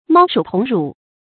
貓鼠同乳 注音： ㄇㄠ ㄕㄨˇ ㄊㄨㄙˊ ㄖㄨˇ 讀音讀法： 意思解釋： 見「貓鼠同眠」。